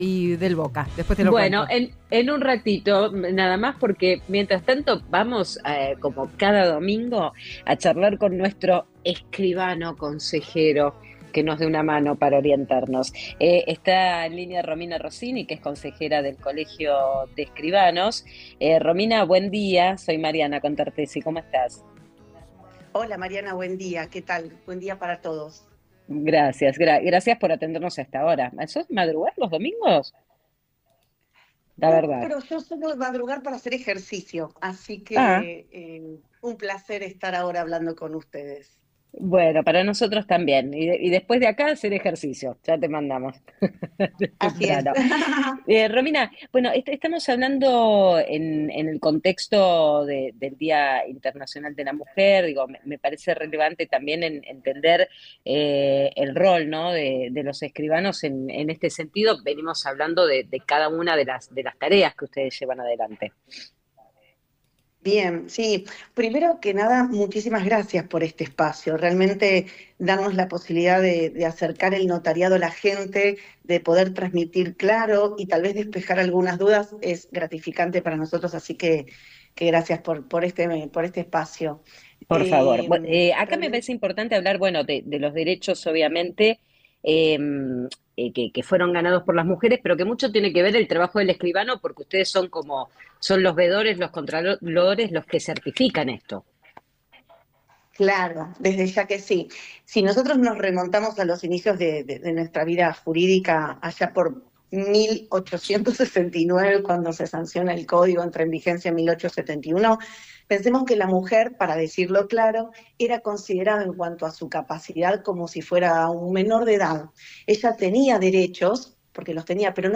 Columna en Radio La Red